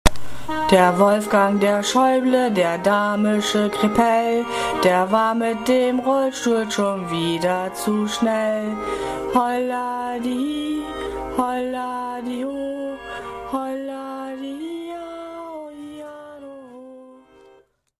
Gstanzl, Jodeln